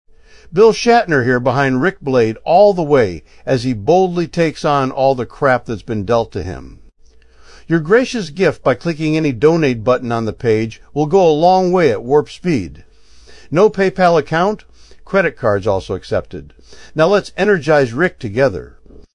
Promo Demos
EndorsementWilliamShatner.mp3